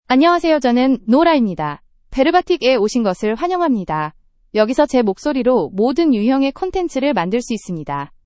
Nora — Female Korean (Korea) AI Voice | TTS, Voice Cloning & Video | Verbatik AI
Nora is a female AI voice for Korean (Korea).
Voice sample
Female
Nora delivers clear pronunciation with authentic Korea Korean intonation, making your content sound professionally produced.